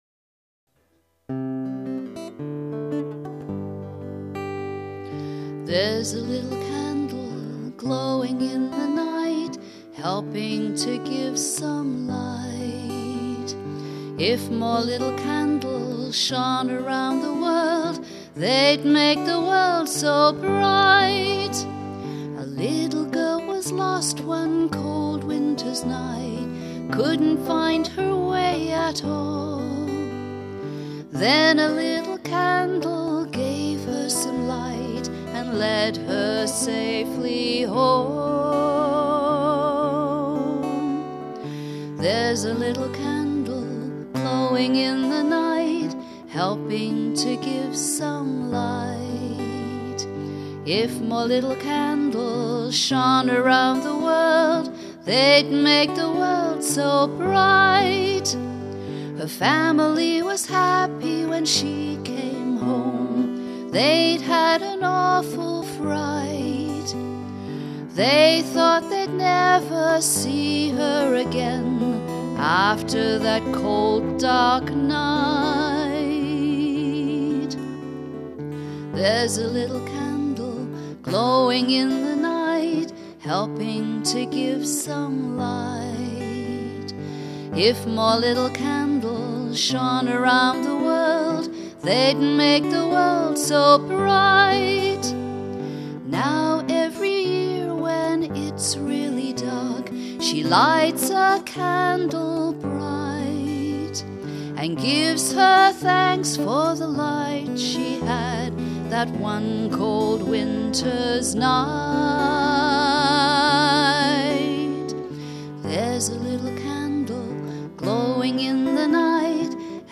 Recorded at Cactus Studios, Grand Forks, BC.